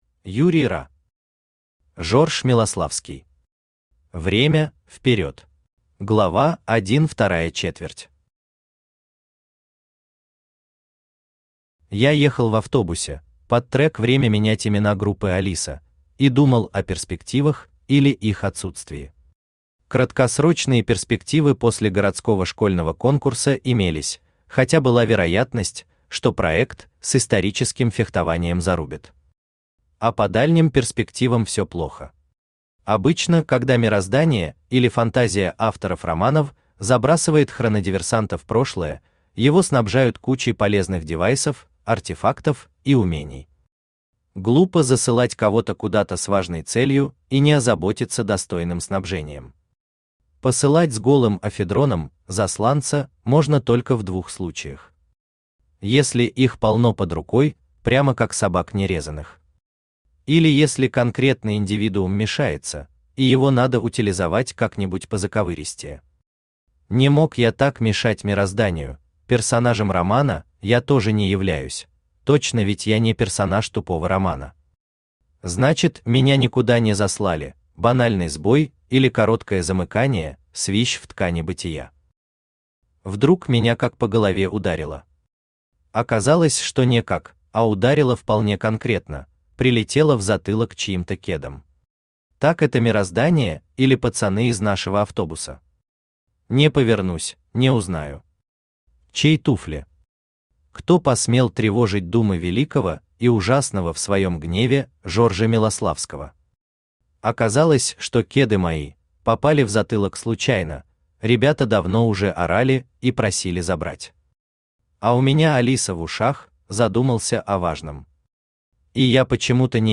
Аудиокнига Жорж Милославский. Время – вперед!
Автор Юрий Ра Читает аудиокнигу Авточтец ЛитРес.